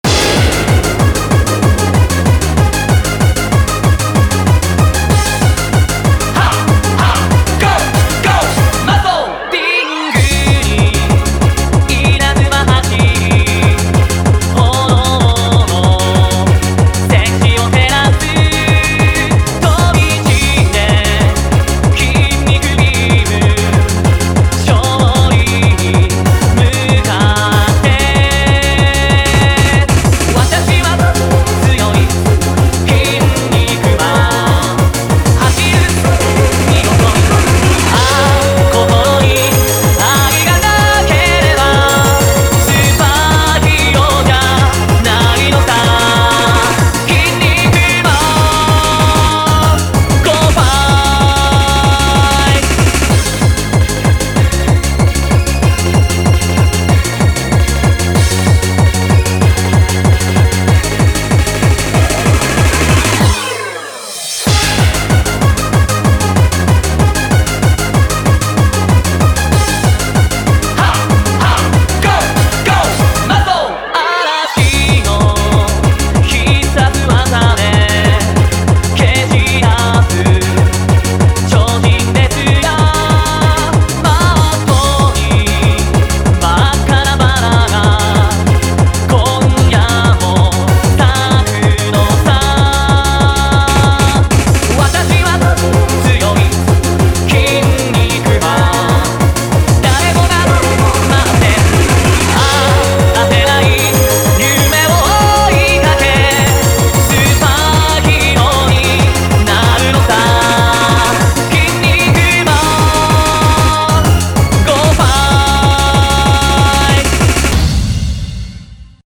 BPM190-190
Audio QualityPerfect (High Quality)
Remix